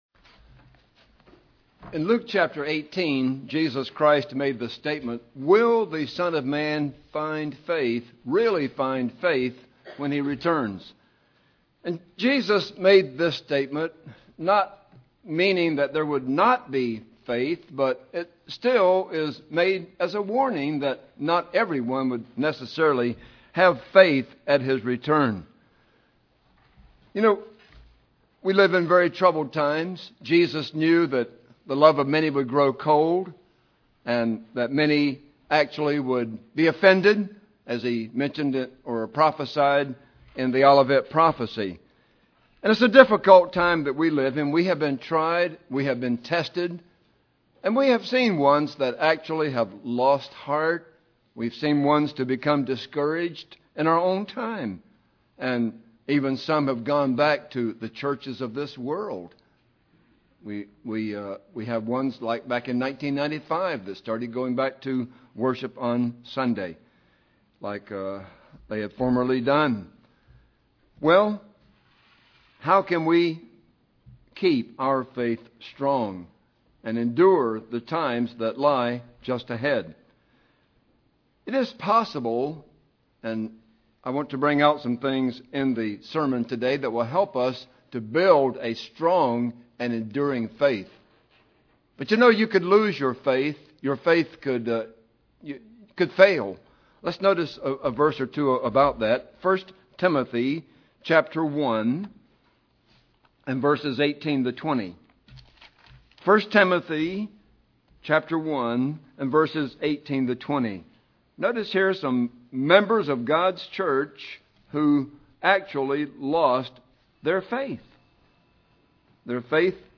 Will Christ find faith on this earth at His return? In this sermon the presenter answers this question and then gives 12 keys to strengthen and build an enduring faith.